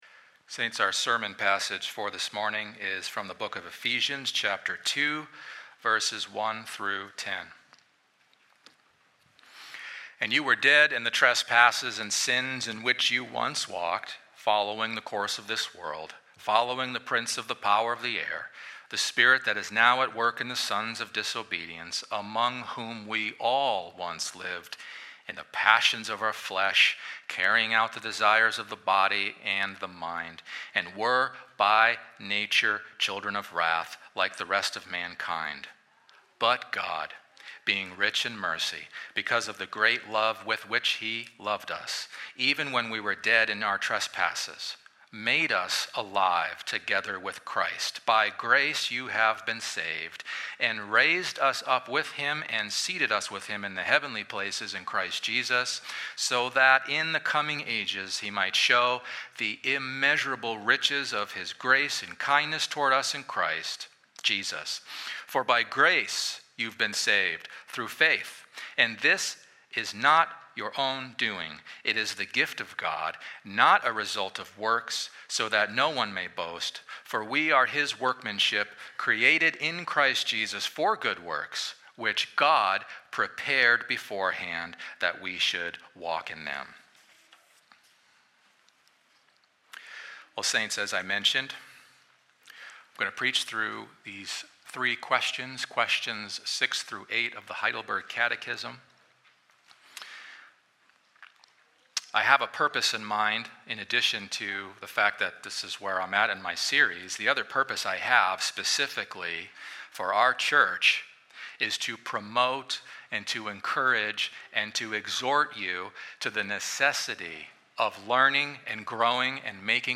preaches further on the faith delivered once for all delivered to the saints.